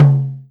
S_hiTom_5.wav